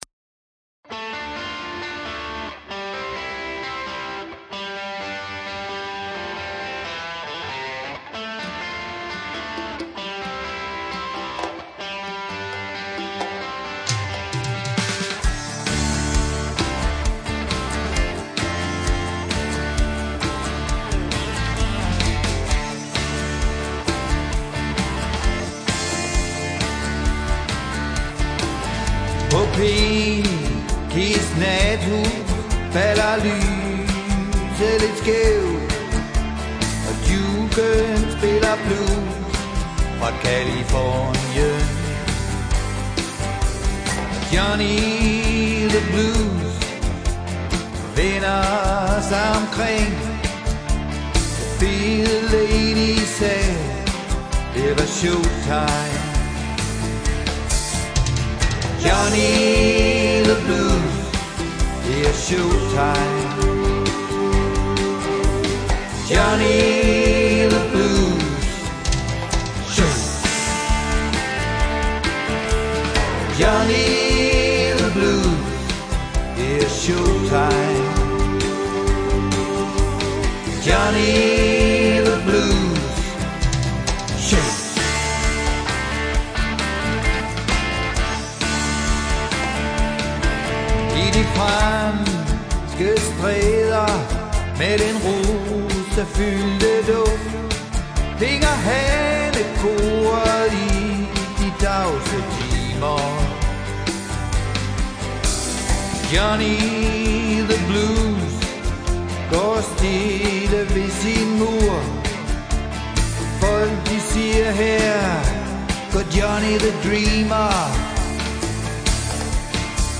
Home studio semi live